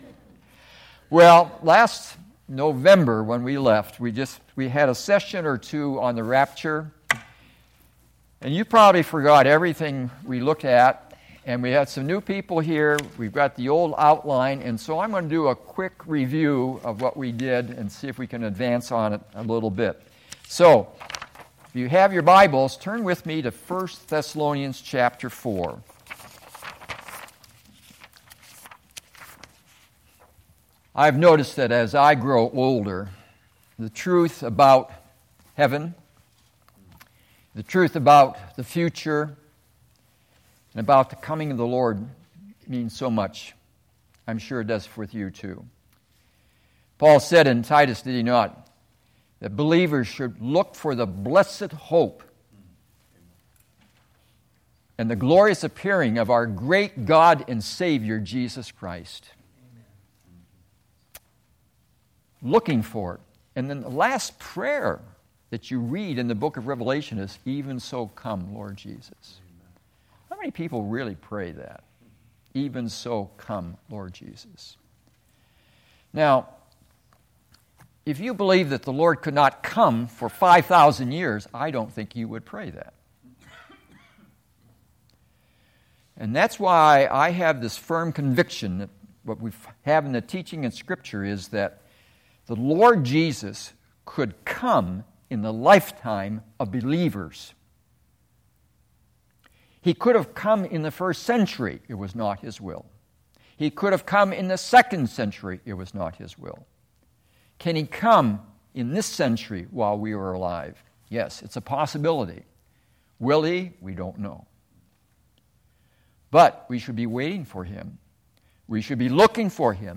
Sunday Evening Message